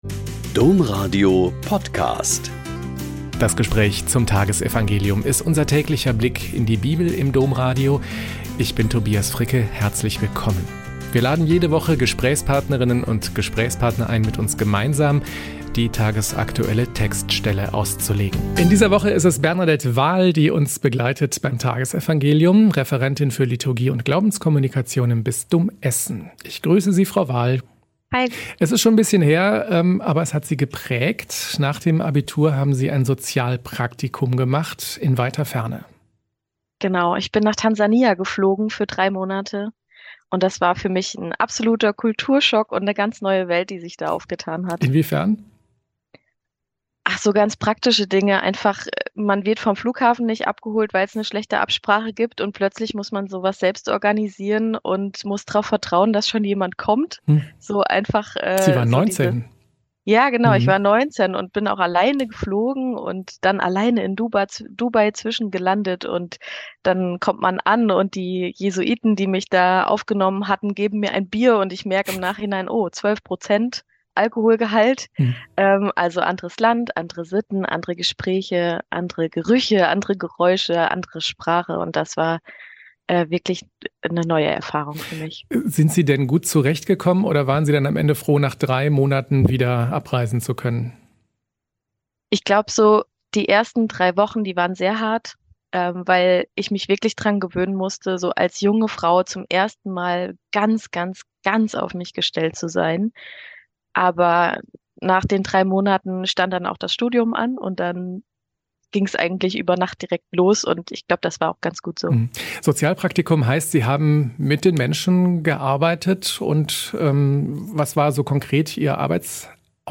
Mk 12,13-17 - Gespräch